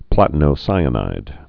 (plătn-ō-sīə-nīd)